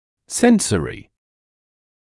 [‘sensərɪ][‘сэнсэри]сенсорный, чувствительный